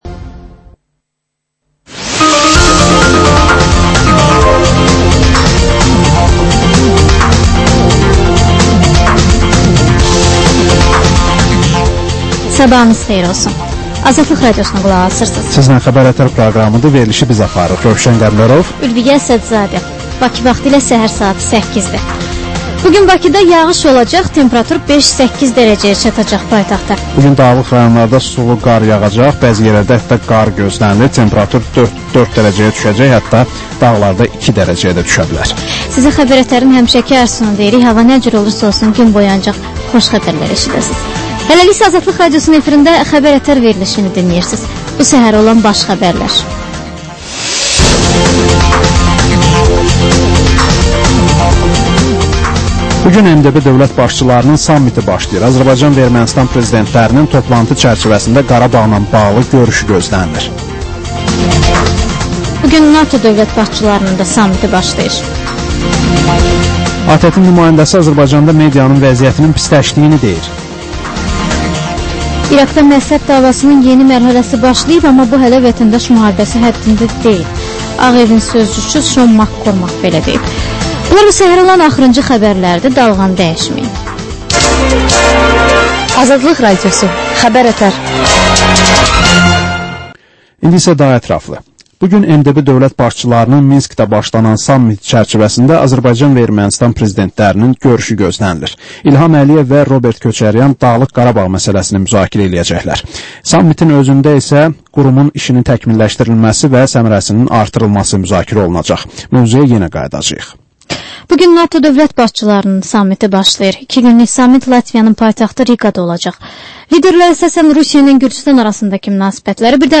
Səhər-səhər, Xəbər-ətər: xəbərlər, reportajlar, müsahibələr.